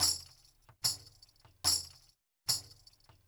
BOL PANDERO.wav